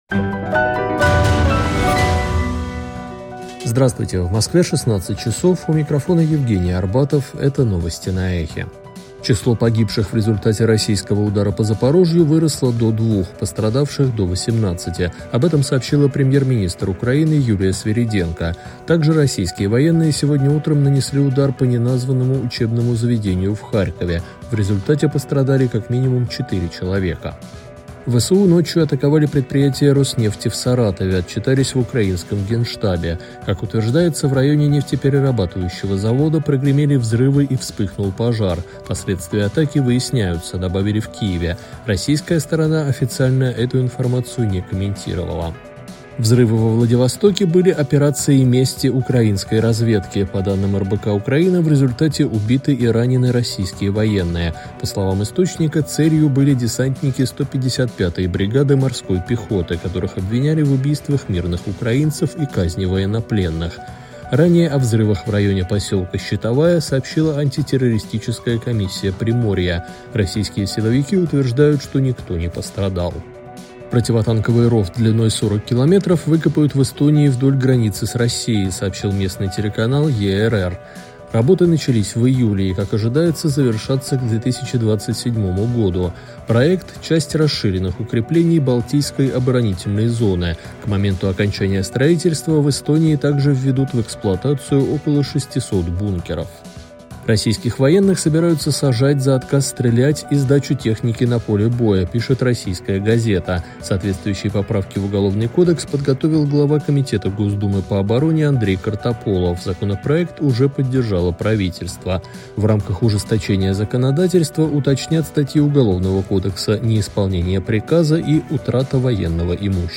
Слушайте свежий выпуск новостей «Эха»
Новости 16:00